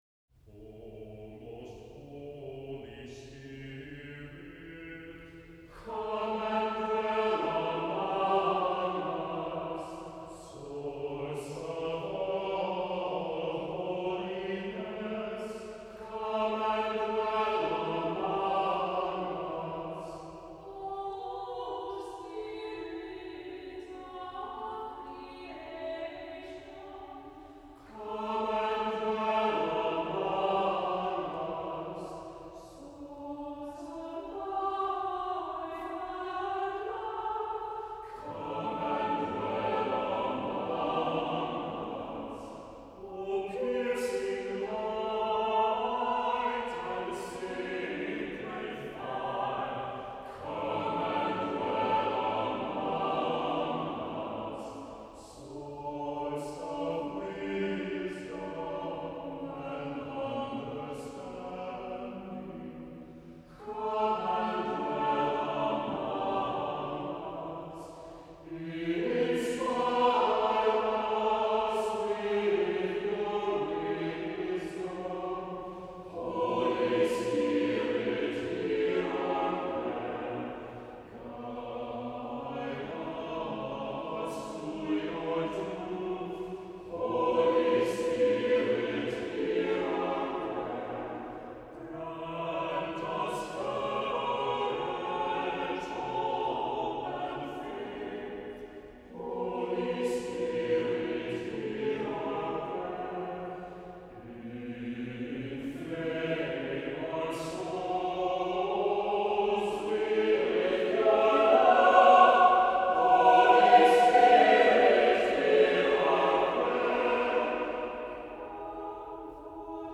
Cantor/SATB/congregation, optional keyboard (c. 3:40)